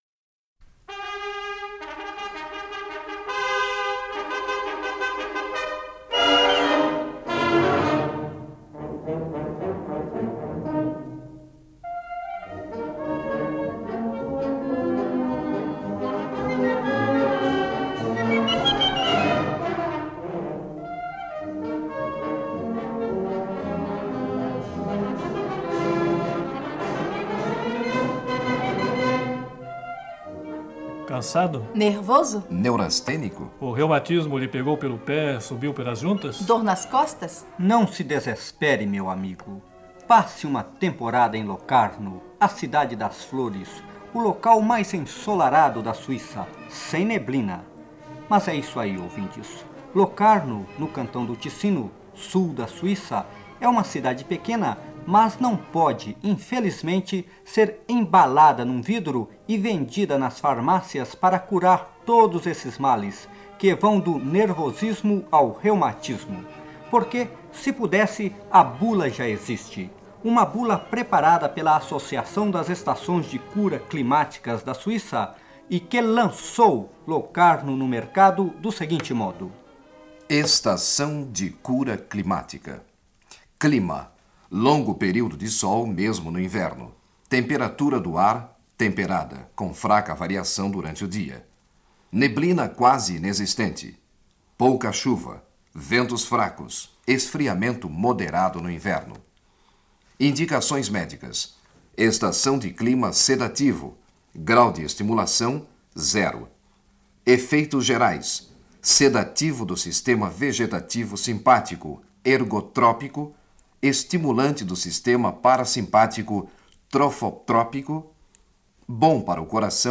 Uma cidade para limpar os pulmões: Locarno. Os jornalistas da SRI Redação de língua portuguesa visitando Locarno em 1977.
Programa de rádio de 1977 sobre Locarno